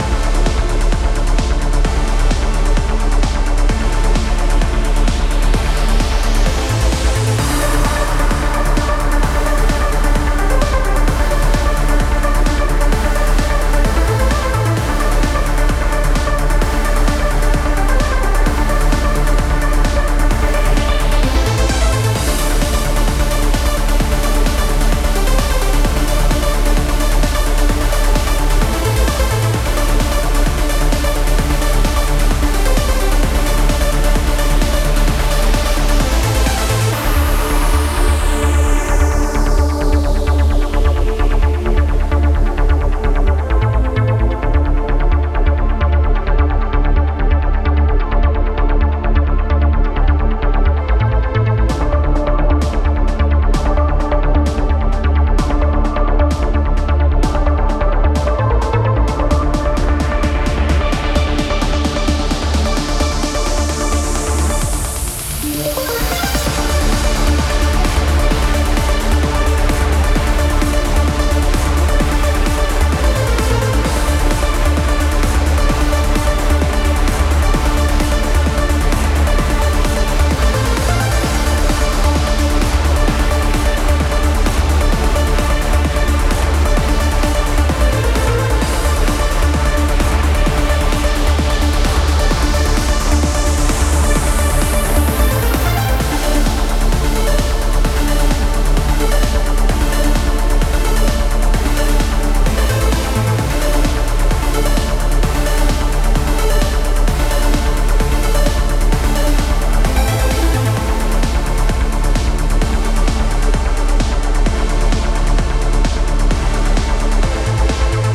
Trance Music for City theme.